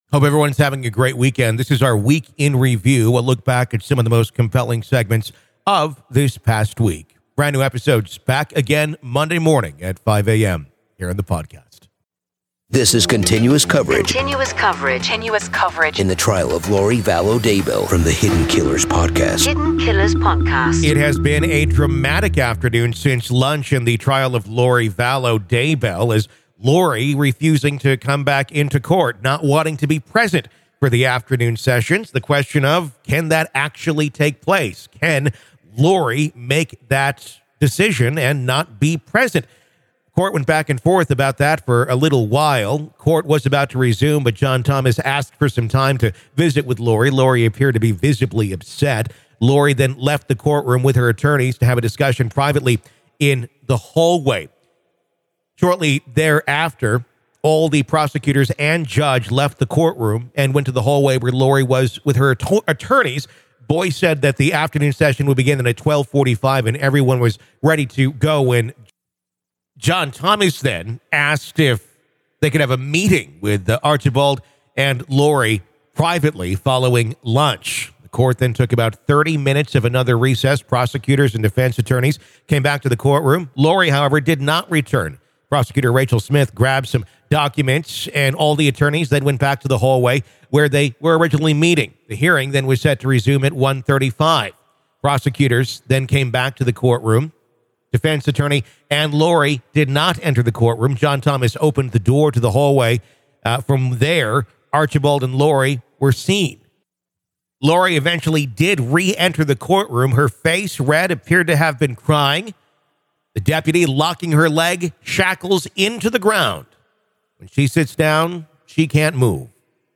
Welcome to "The Week In Review," a riveting journey that takes you back through the most captivating interviews, gripping updates, and electrifying court audio from the cases that have captured our attention.